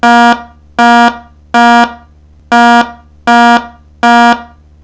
Loudenlow smoke detector for hearing impaired, low frequency
LOUDENLOW™ MODEL 1000 (Middle C) Louder and more powerful than typical smoke alarms.